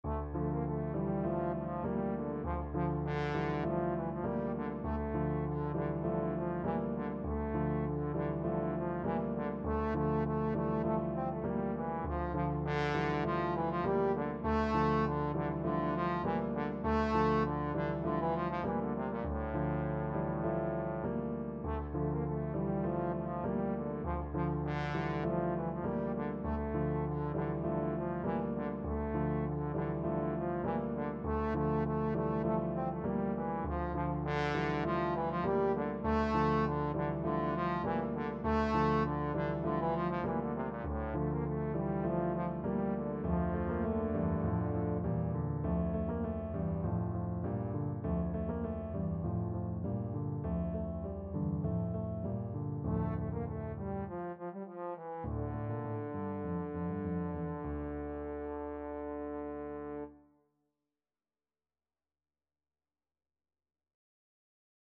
Classical Tchaikovsky, Pyotr Ilyich U Vorot (At the Gate) from 1812 Overture Trombone version
D minor (Sounding Pitch) (View more D minor Music for Trombone )
Allegro (View more music marked Allegro)
4/4 (View more 4/4 Music)
Classical (View more Classical Trombone Music)